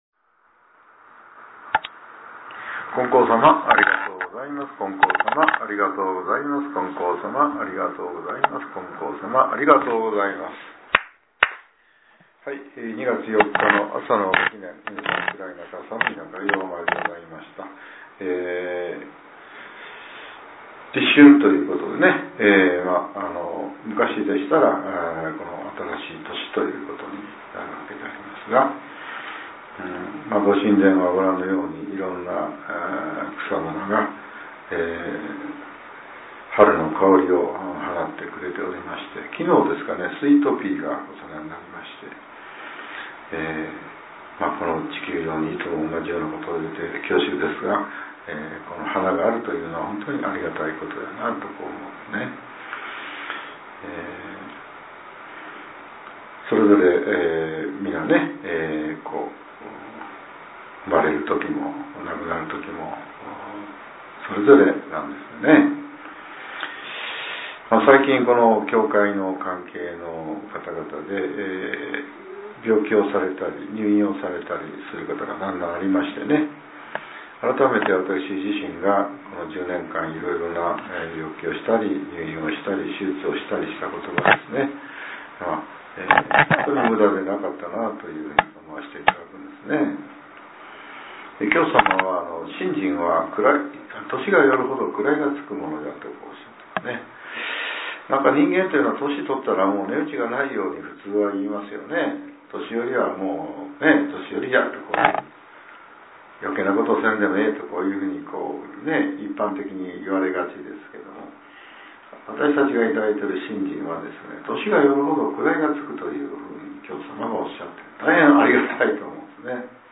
令和８年２月４日（朝）のお話が、音声ブログとして更新させれています。